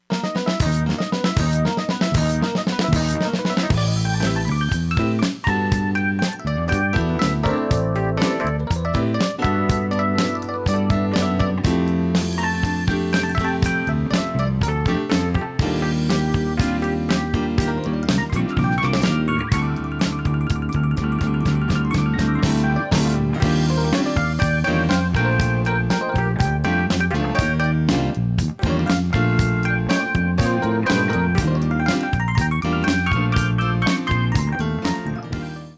screaming piano and hammond solos
accompianment from a backing band